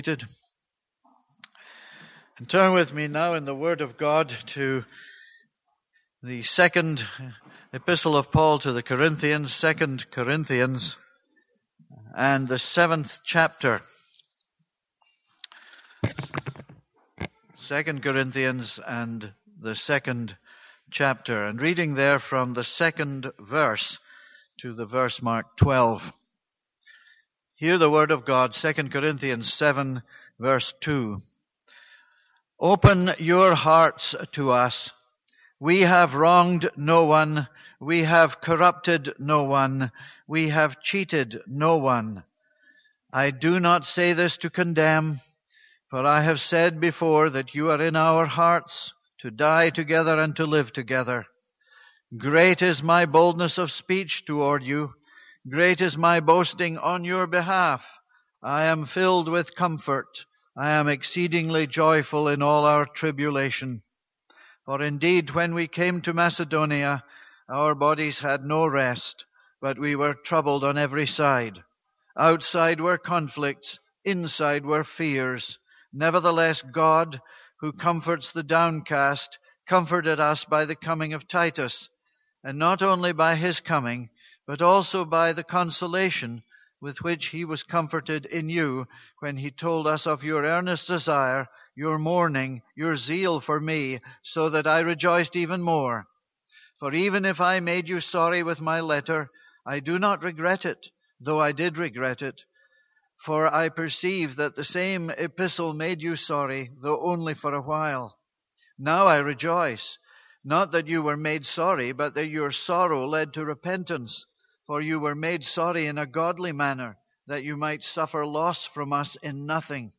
This is a sermon on 2 Corinthians 7:2-4.